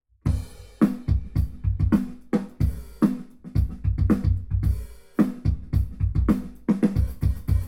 We provide binaural renderings of a drum sample convolved with directional room impulse responses (DRIRs) and different modifications thereof.
The DRIR was measured in a small lab room using the Eigenmike em32 allowing up to fourth-order spherical harmonics decomposition. In this example, the direct sound and the 15 most prominent reflections were extracted.